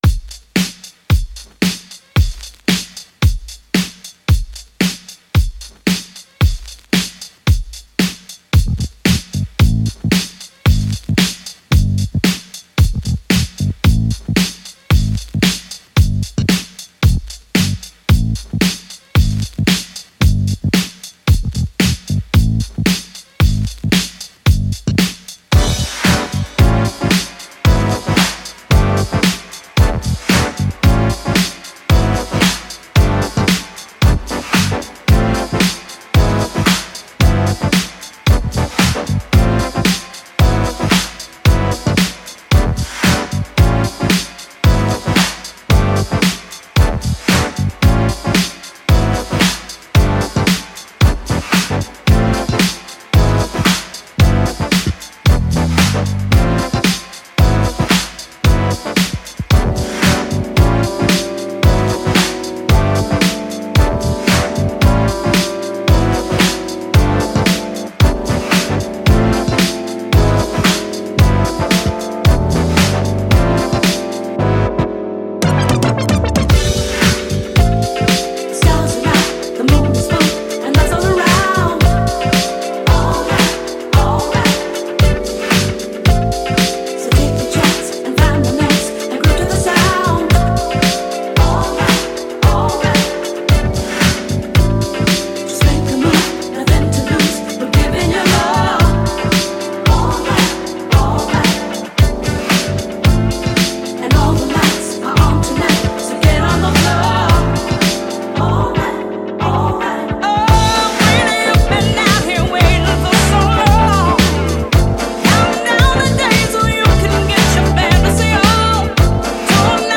funk-laced rhythms straddling house, disco and boogie
the soulful keys